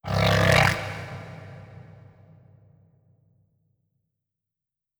khloCritter_Male18-Verb.wav